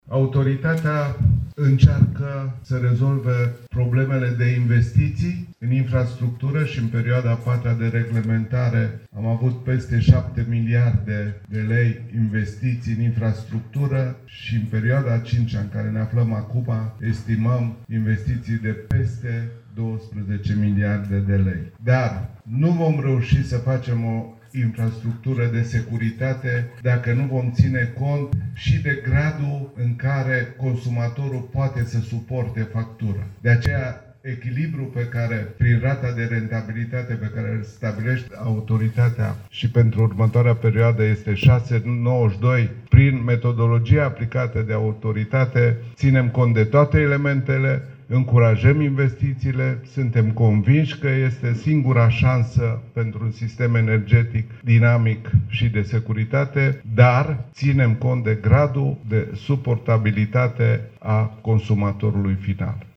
Conferința SMART ENERGY 2025 organizată azi la Constanța a adus în prim plan energia și, mai precis, viitorul energiei inteligente între inovație, digitalizare și eficiență.